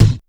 Kick_57.wav